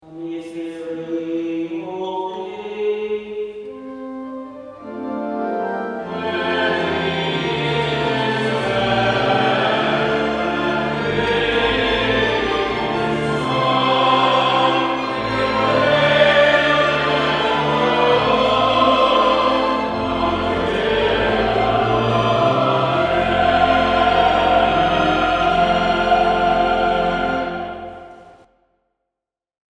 Lakewood Cluster Choirs of Saint Clement, Saint James and Saint Luke Sang this Song
2023 Easter Vigil